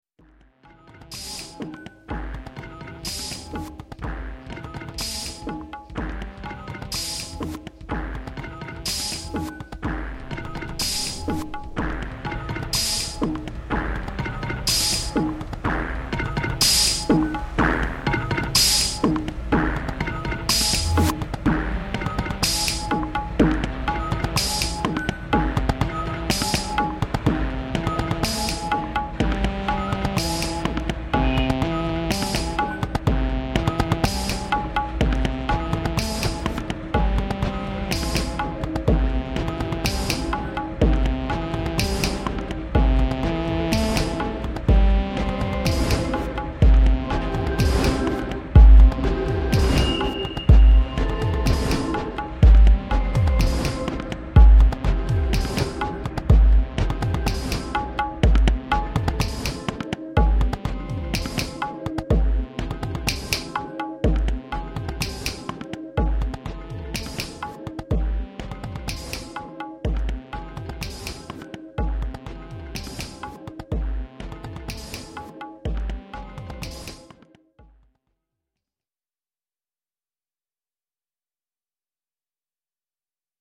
Hier gibt es meine Experimente mit Tönen und Geräuschen sowie diverser Hard- und Software zur Klangerzeugung.
audio-gueterzug.mp3